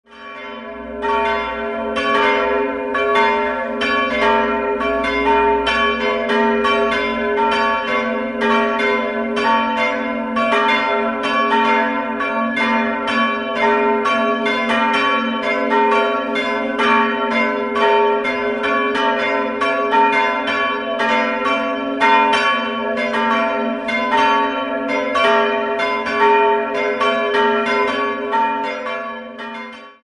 Pfarrkirche St. Hippolyt Glocken Gel�ute Quelle